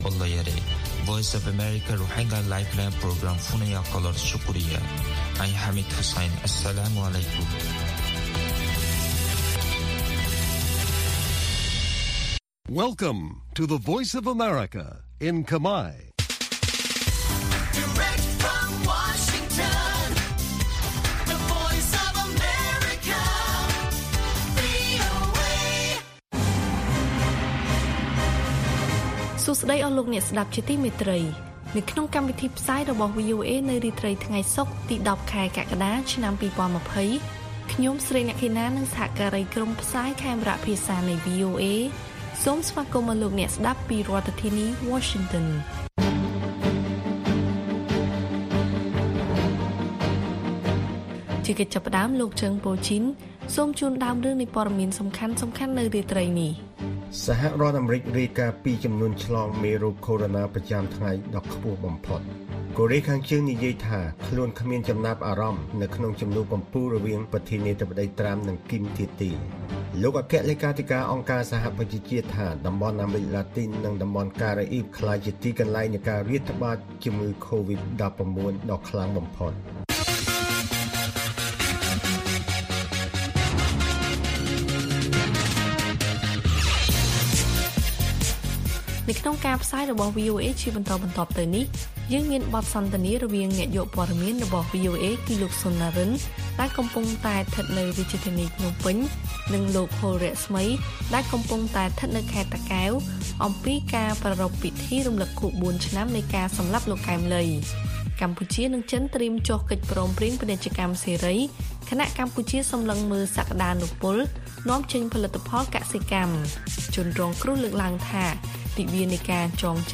ព័ត៌មានសំខាន់ៗអំពីកម្ពុជាមានដូចជា ចិននិងកម្ពុជាត្រៀមចុះកិច្ចព្រមព្រៀងពាណិជ្ជកម្មសេរី ខណៈកម្ពុជាសម្លឹងមើលសក្តានុពលនាំចេញផលិតផលកសិកម្ម។ បទសន្ទនារវាងអ្នកយកព័ត៌មានឲ្យវីអូអេ៖ ពិធីរំឭកខួប៤ឆ្នាំនៃមរណភាពលោកកែម ឡី ត្រូវបានធ្វើឡើងដោយមានការតាមដាននិងរឹតត្បិតពីអាជ្ញាធរ។